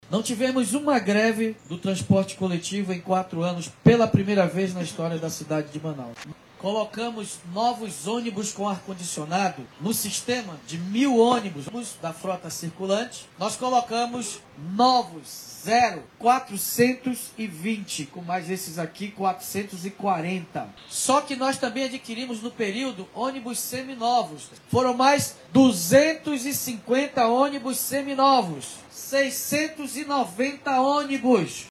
SONORA-1-PREFEITO.mp3